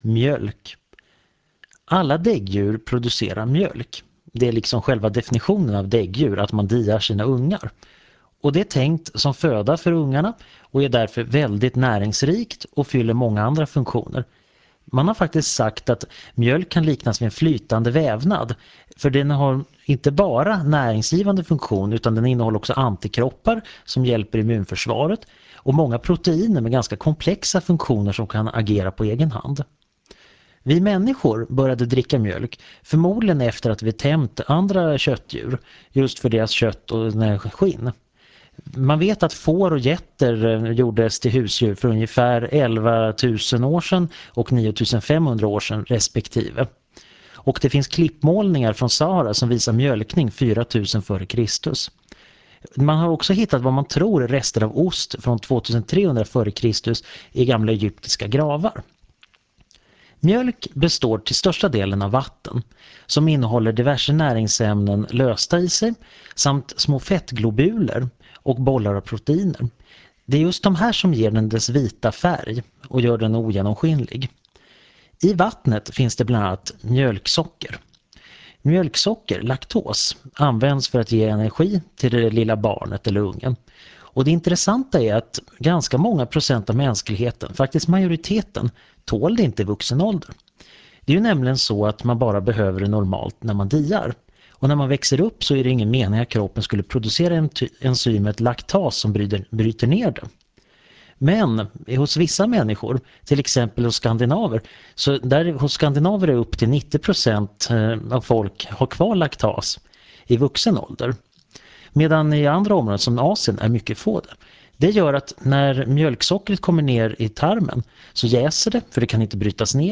Föredraget handlar om biologi och sänds den 08 oktober 2000 i Förklarade_Fenomen.